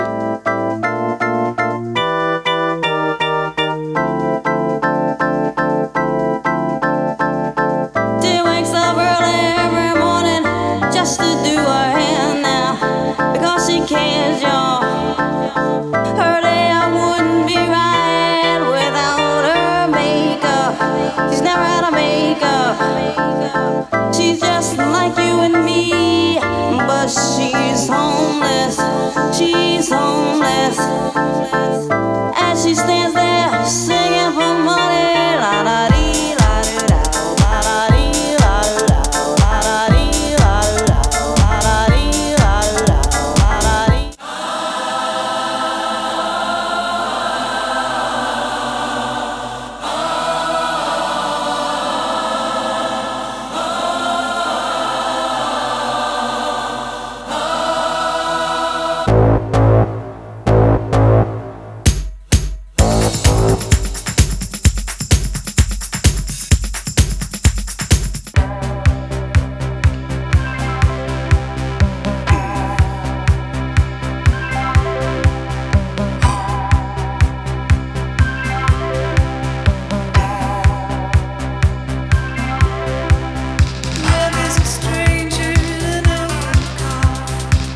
Muziek komt van WAV (geconverteerd van CD audio naar WAV) en is ook opgeslagen in WAV voor de beste weergave van de instellingen (download is +/- 16 MB).
Band 5 dient om de stemmen een beetje beter te de-essen.